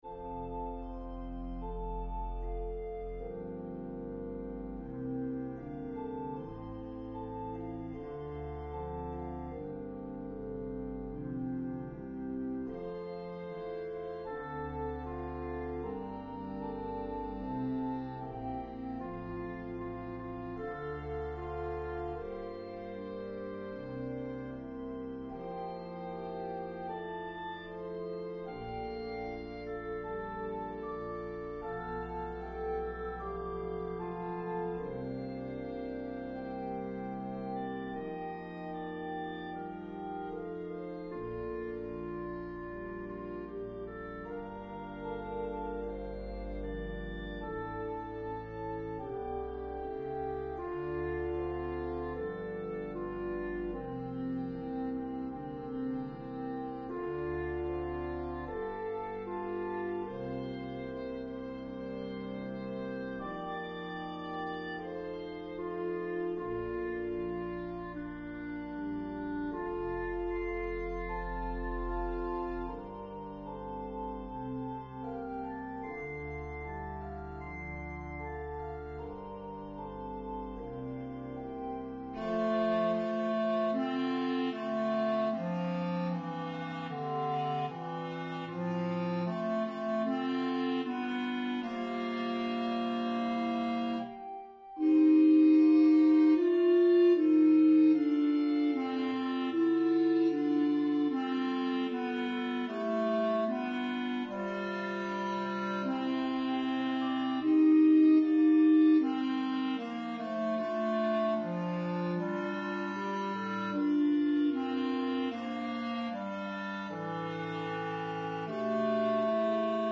For the fallen RR Tenor